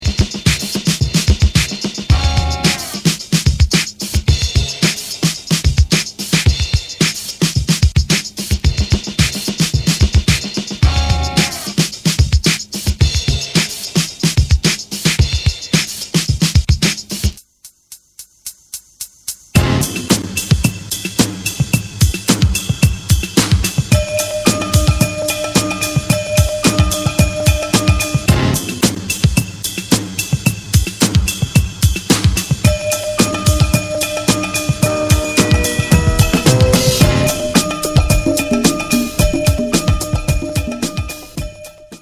trip-hop